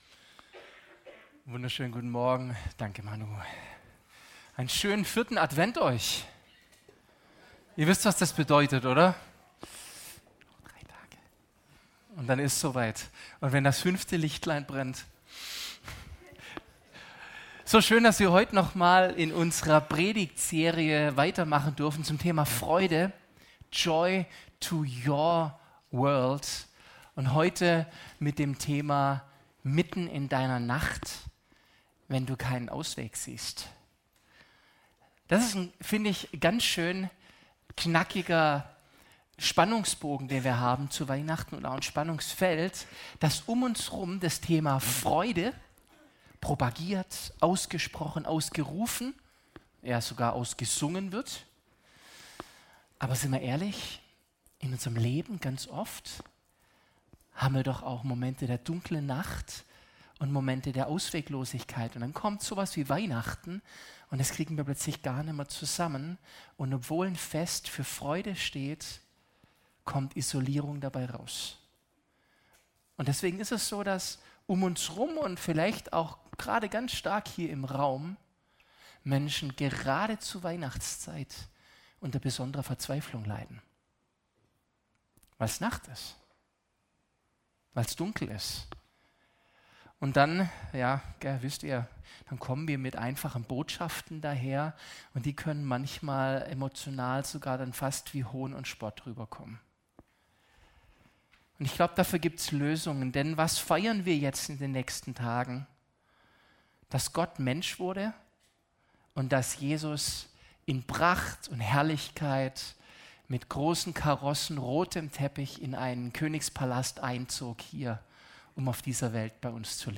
Diese Predigt nimmt genau diese Realität ernst und zeigt: Weihnachten ist nicht romantische Verklärung, sondern eine kraftvolle Botschaft hinein in unsere Nacht. Jesus kommt nicht im Palast, sondern im Stall zur Welt – kalt, zugig, dunkel, mitten in der Nacht.